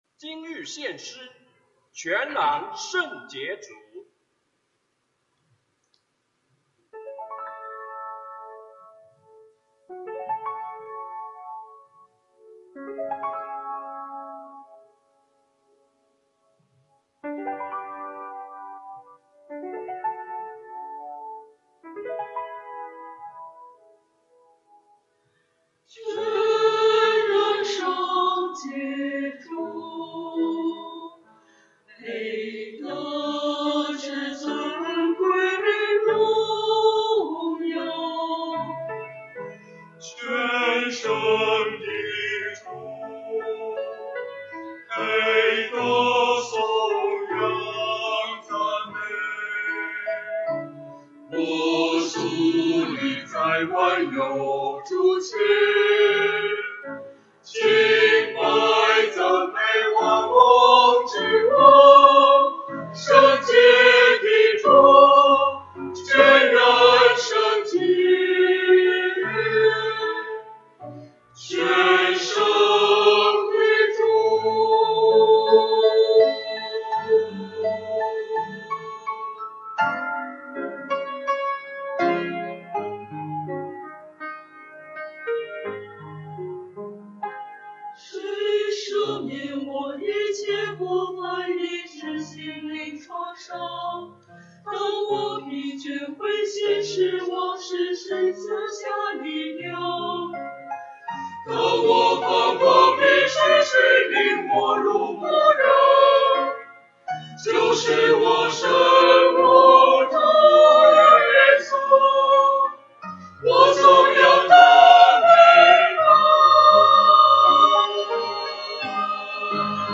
团契名称: 大诗班 新闻分类: 诗班献诗 音频: 下载证道音频 (如果无法下载请右键点击链接选择"另存为") 视频: 下载此视频 (如果无法下载请右键点击链接选择"另存为")